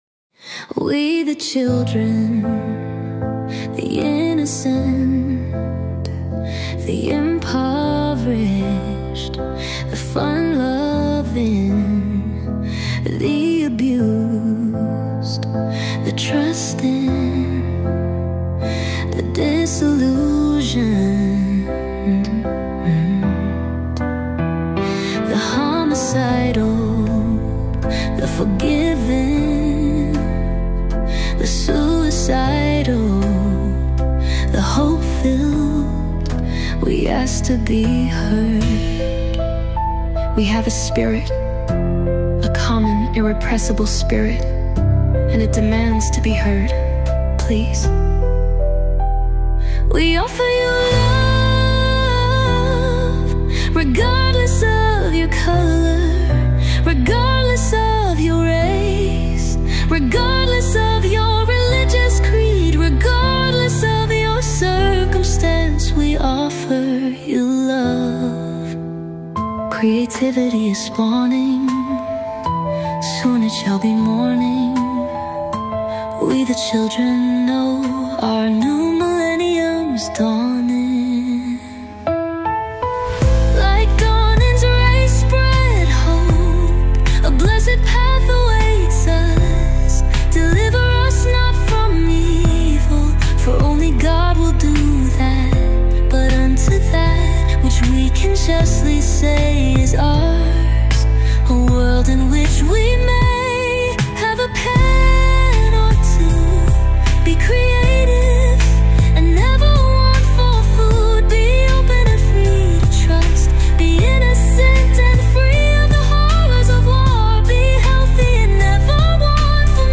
Young females are adding their voice to the